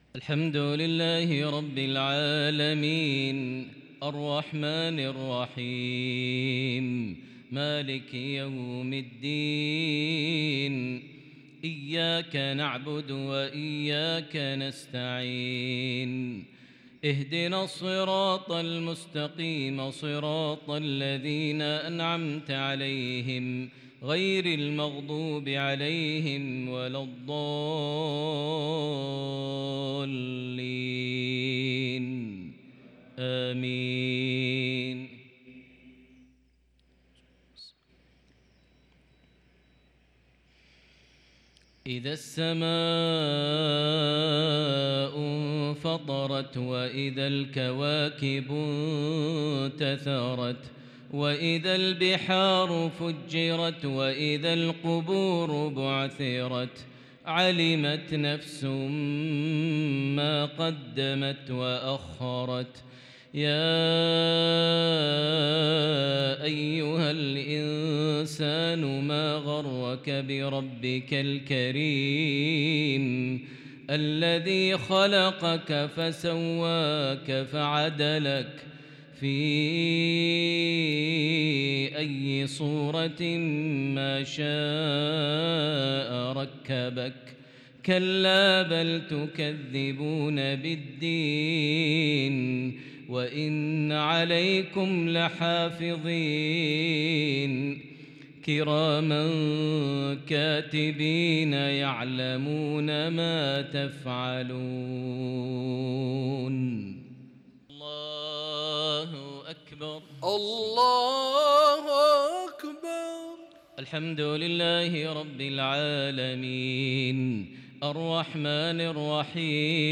صلاة المغرب للشيخ بندر بليلة 11 ربيع الأول 1444هـ ۞ سورة سبأ 24 - 30